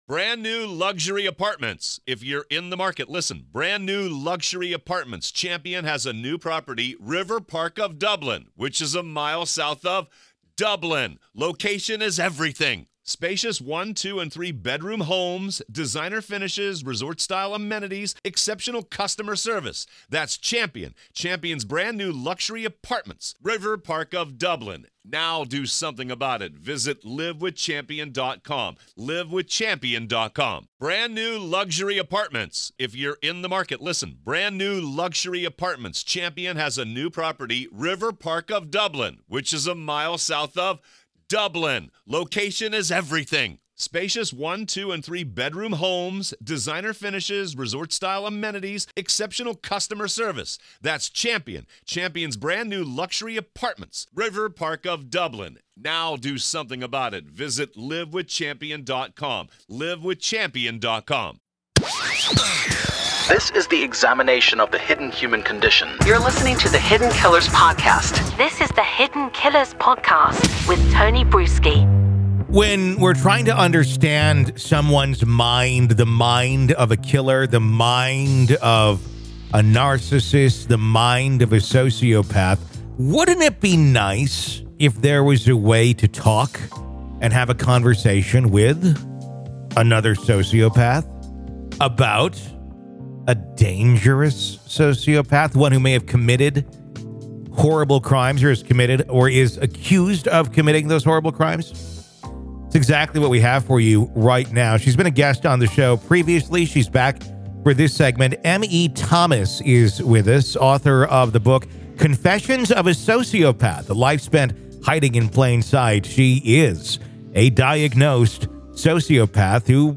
In a riveting conversation